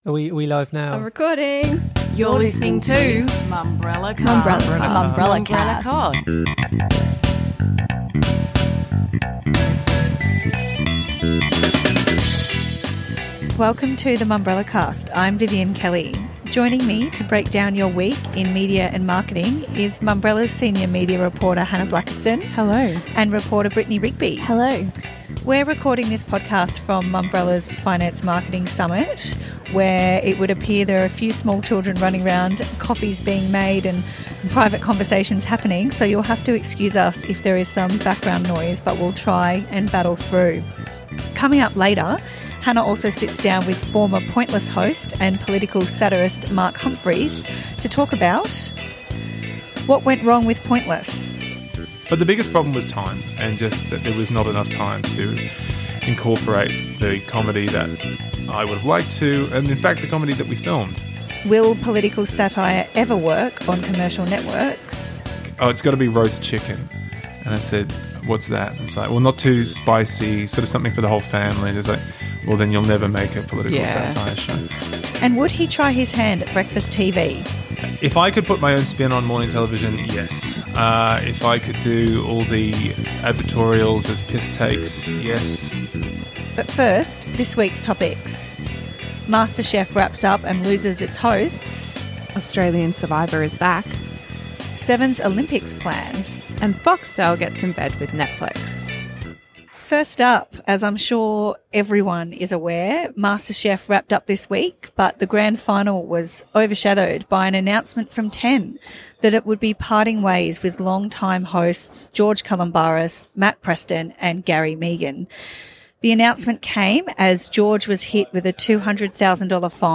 It was a big week in TV and the Mumbrella team takes time out during the Finance Marketing Summit to talk it all through. George Calombaris was caught out yet again for underpaying his staff.